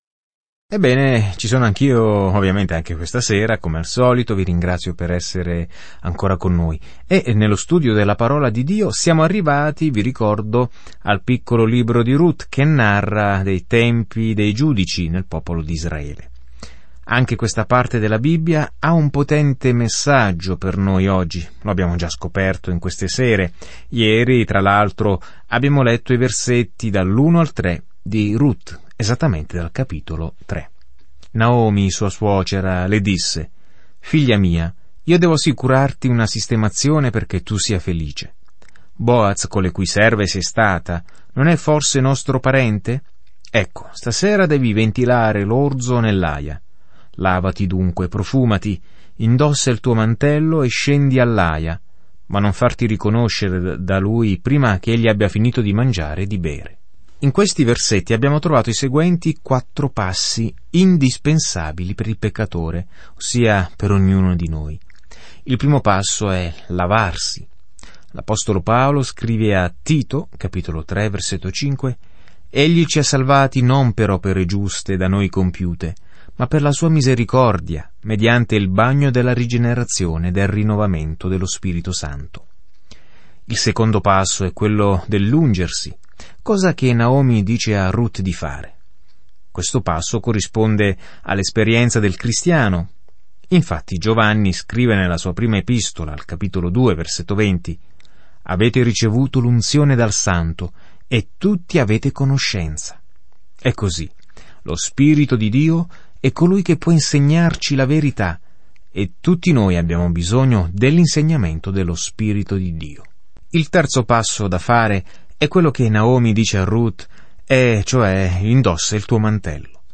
Scrittura Rut 3:1-18 Giorno 7 Inizia questo Piano Giorno 9 Riguardo questo Piano Ruth, una storia d'amore che rispecchia l'amore di Dio per noi, descrive una lunga visione della storia, incluso il retroscena di re Davide... e persino di Gesù. Viaggia ogni giorno attraverso Ruth mentre ascolti lo studio audio e leggi versetti selezionati della parola di Dio.